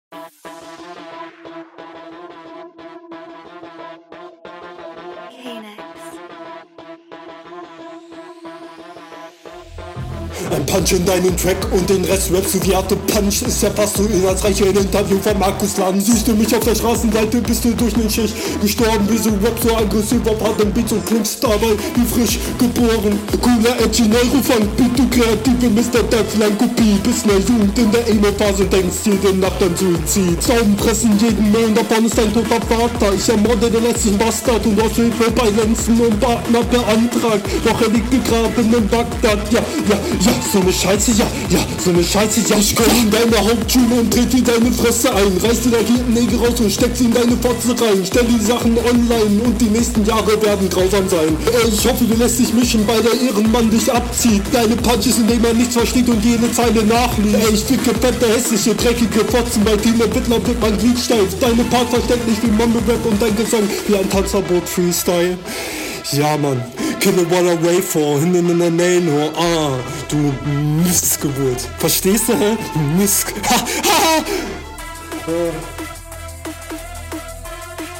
Flow: Dein Flow ist nicht so gut wie der von deinem Gegner. Oft nicht tight.